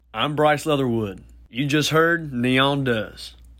LINER Bryce Leatherwood (Neon Does) 5
LINER-Bryce-Leatherwood-Neon-Does-5.mp3